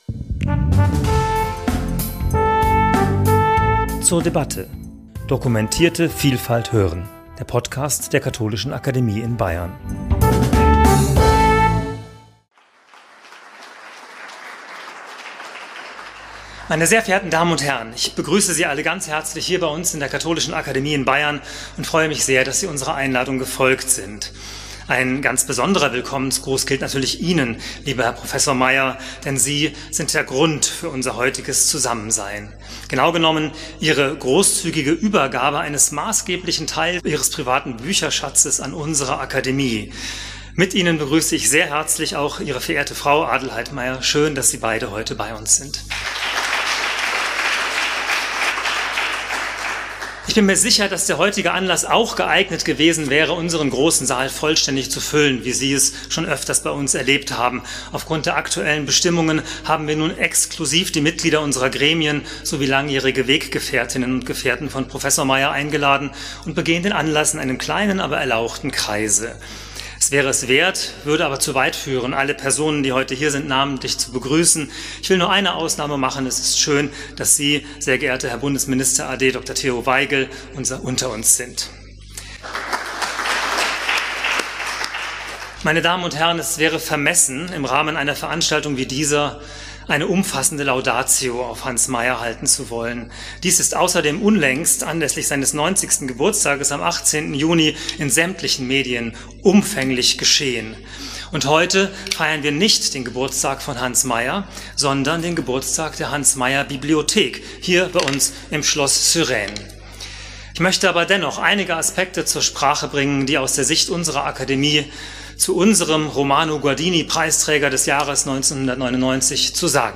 Gespräch zur Eröffnung der Hans Maier-Bibliothek ~ zur debatte Podcast
Zur Begegnung mit Hans Maier anlässlich der Eröffnung der professionell eingerichteten Bibliothek waren Mitglieder der Akademie-Gremien sowie enge Weggefährten von Professor Maier gekommen.